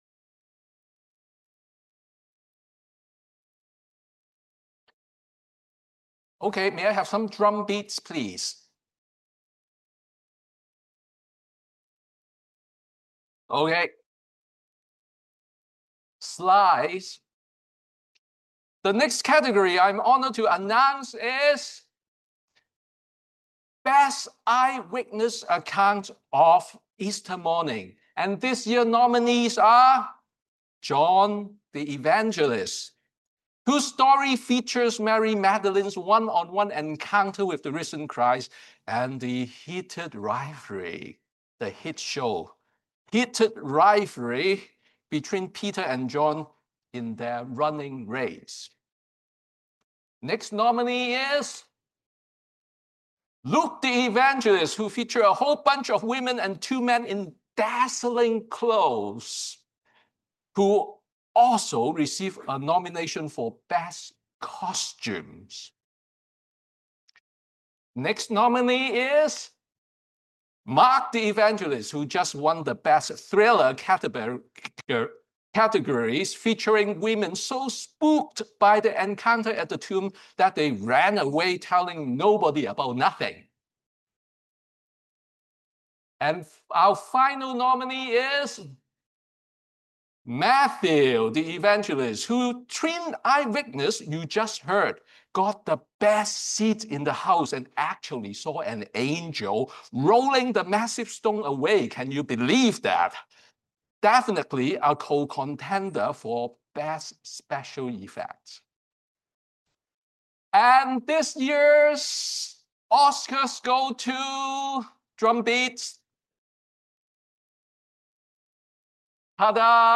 Sermon on Easter Sunday